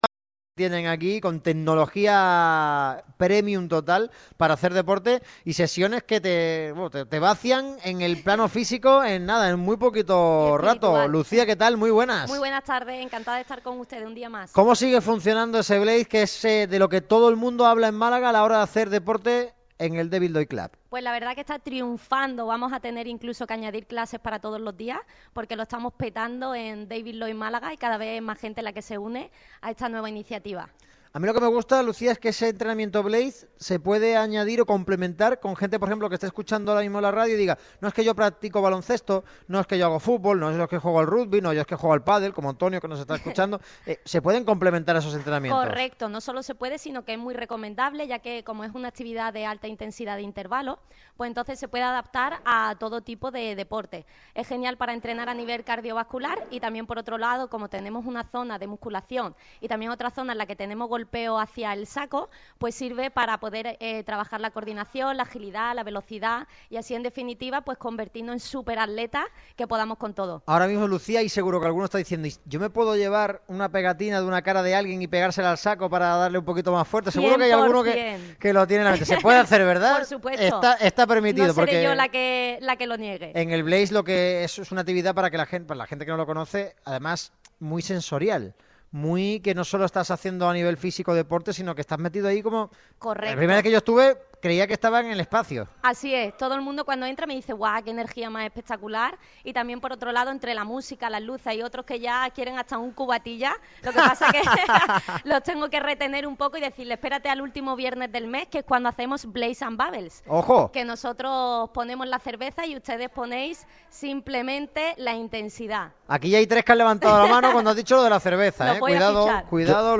Este miércoles 24 de enero, el programa de Radio MARCA Málaga ha visitado el complejo deportivo David Lloyd situado en Pedregalejo.